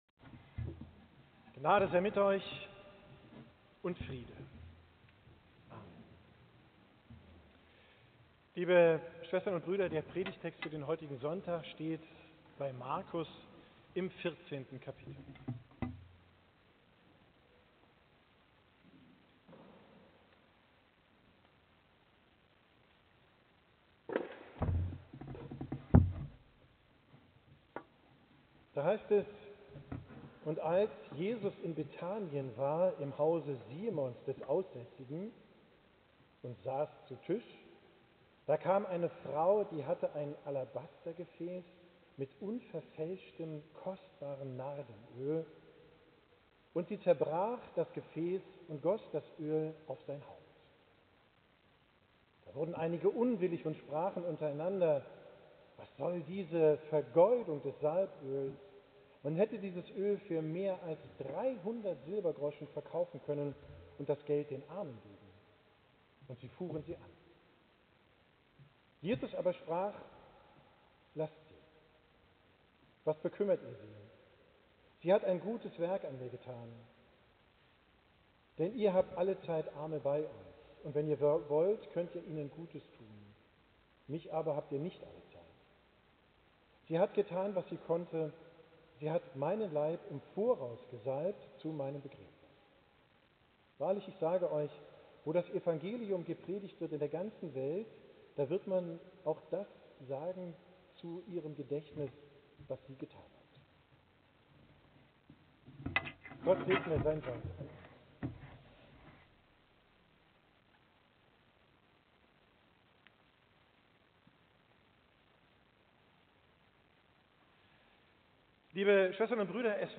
Predigt am Sonntag Palmarum